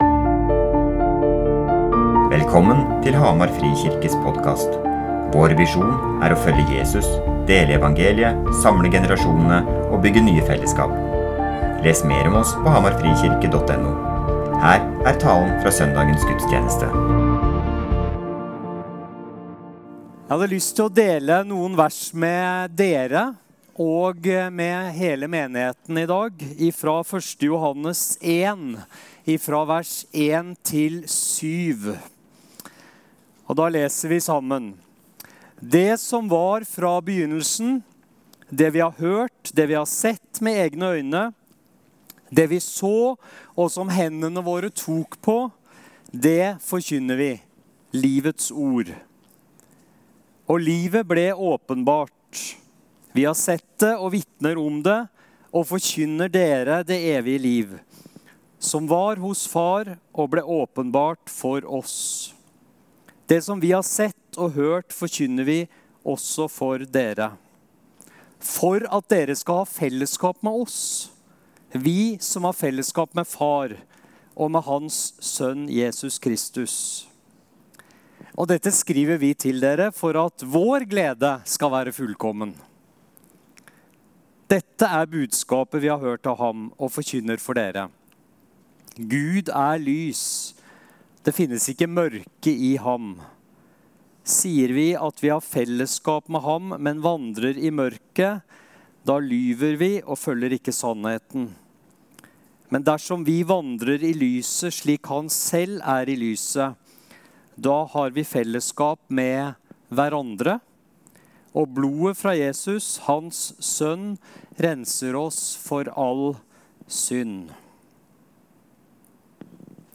Gjestetaler
Gudstjenesten